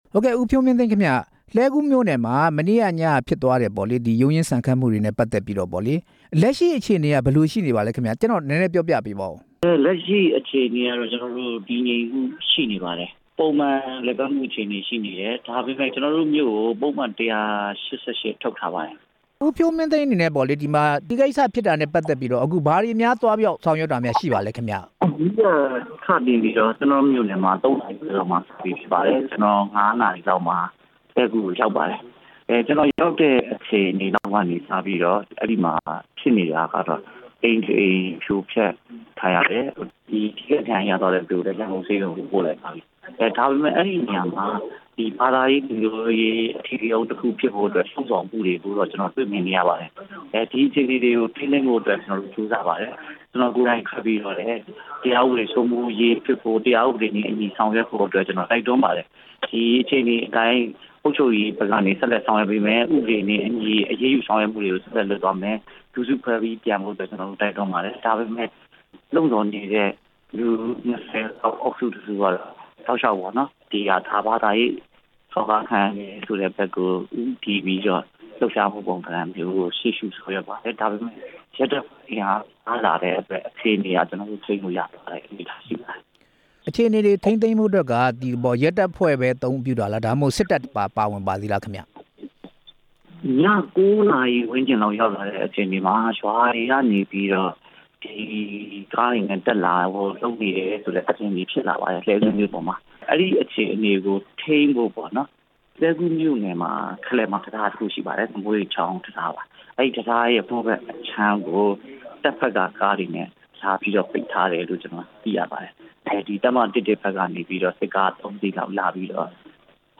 လွှတ်တော်ကိုယ်စားလှယ် ဦးဖြိုးမင်းသိန်းနဲ့ မေးမြန်းချက်